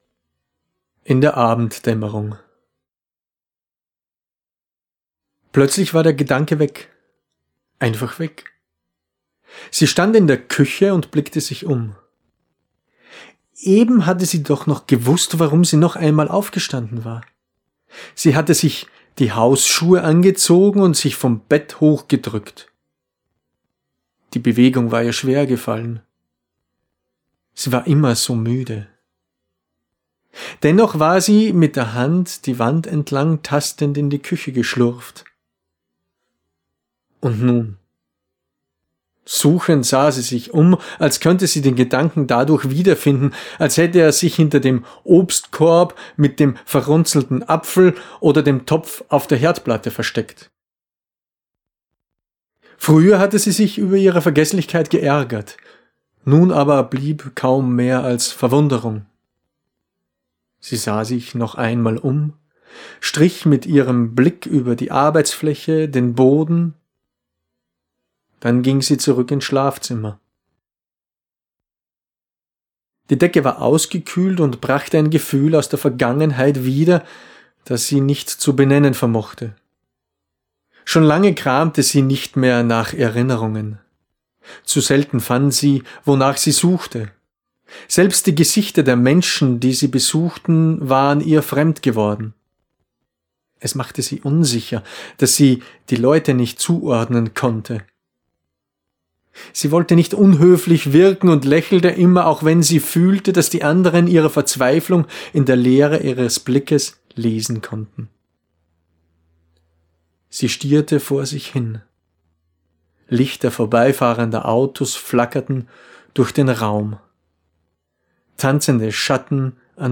Erzählung gelesen von